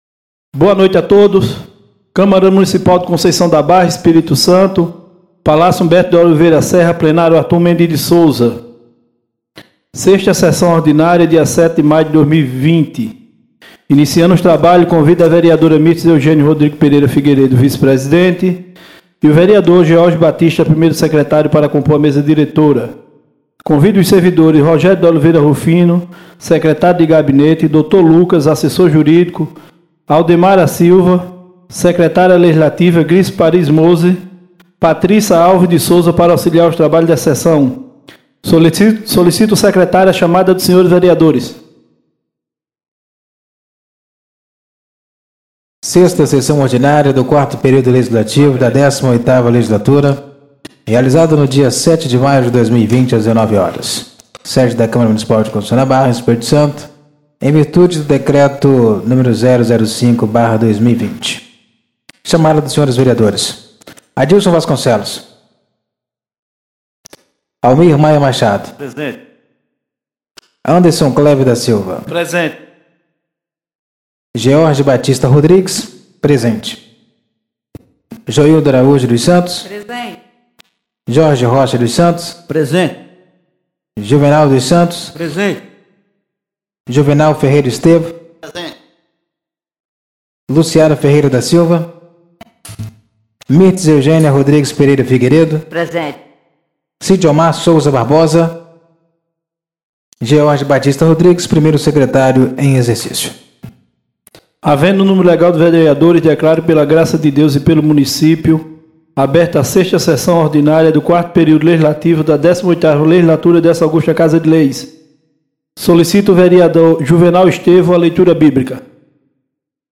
6ª Sessão Ordinária do dia 07 de maio de 2020